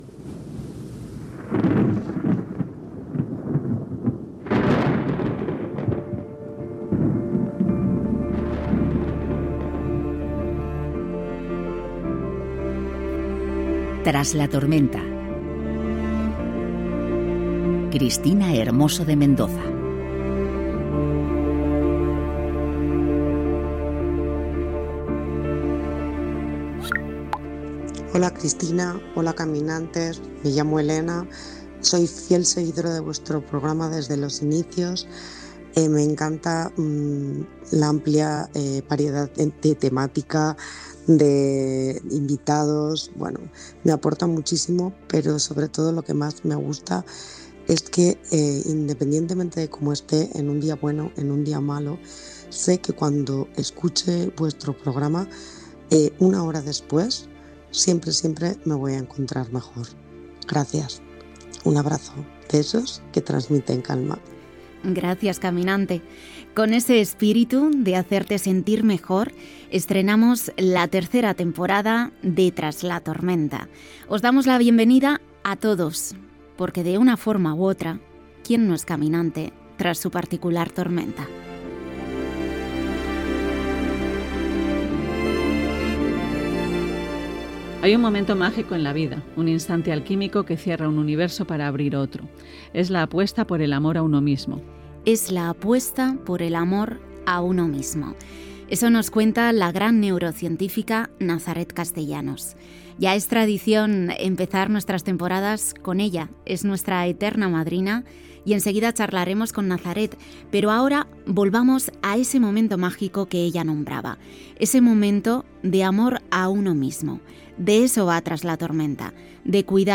Careta del programa, missatge d'una oïdora, benvinguda.
Divulgació